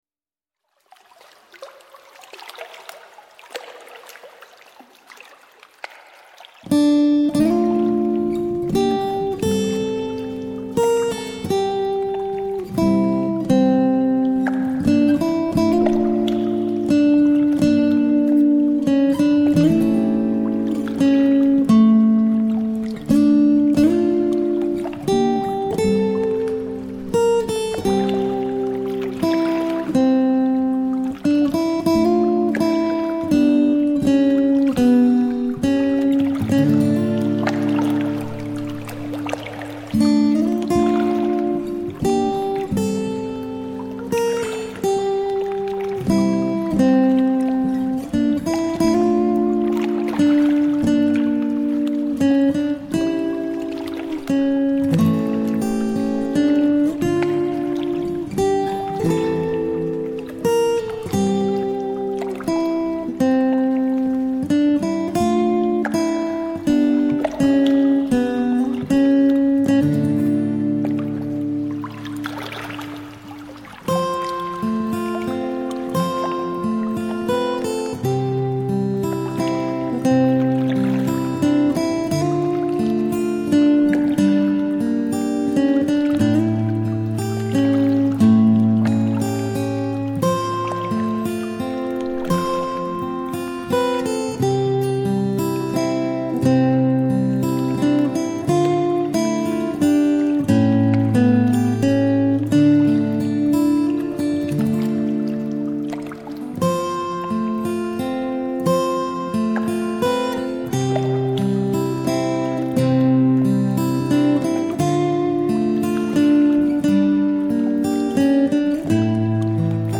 减压静心音乐
清丽如水 飘然若云
琴韵 鸟啼 水流 云动
融汇流淌成一脉美妙乐章
为耳朵找－阙自然的声音，让承载宇宙和谐频率的大自然音效与质朴琴韵引领你进入平和、舒坦的绿色空间，抚平体内跃动的情愫。